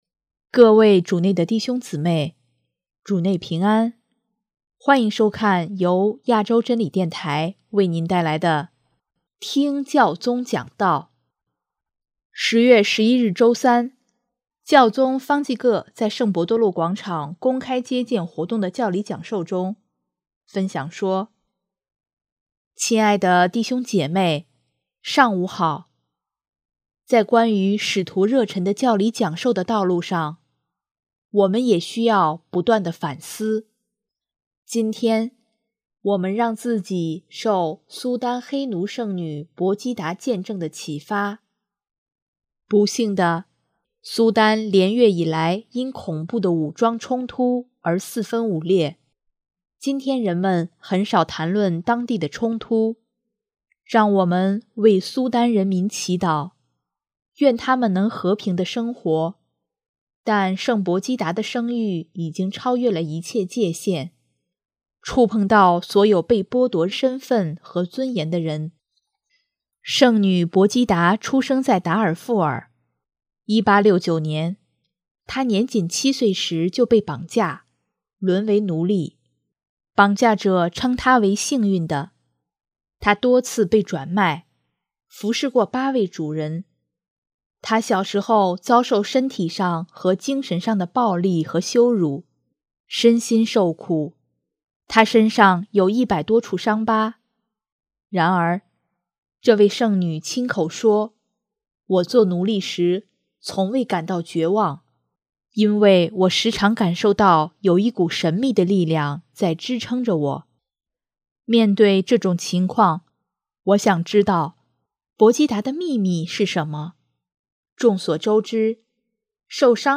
10月11日周三，教宗方济各在圣伯多禄广场公开接见活动的教理讲授中，分享说：